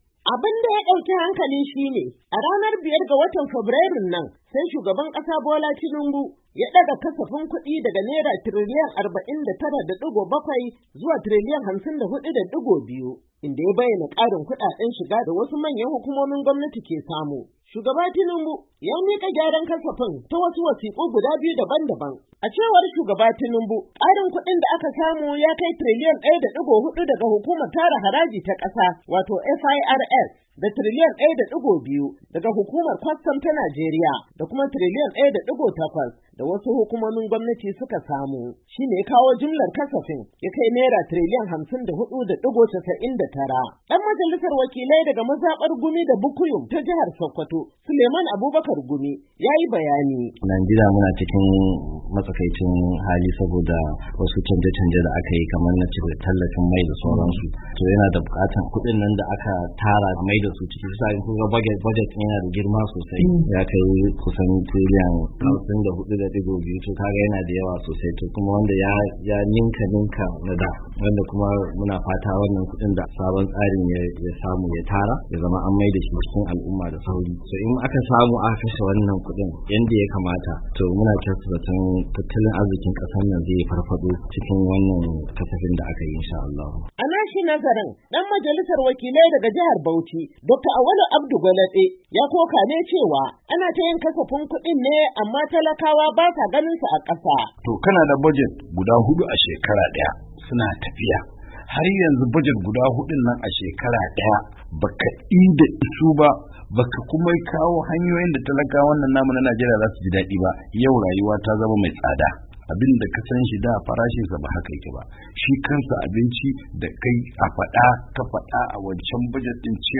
Wasu na ganin haka ya saba yadda ake gabatar da kasafin kudin kasar kamar yadda za ku ji a wannan rahoto.